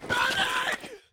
stunned.ogg